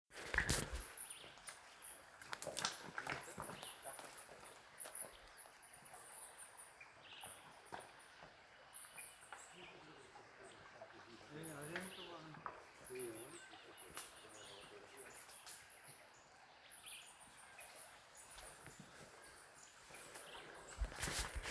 Uniform Finch (Haplospiza unicolor)
Se movian a muy baja altura en la maraña de bambu, ya seco en su mayoria, constantemente emitian llamados de contacto muy breves y agudos.
Life Stage: Fledgling
Location or protected area: Valle de Lunarejo
Certainty: Photographed, Recorded vocal